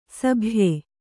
♪ sabhye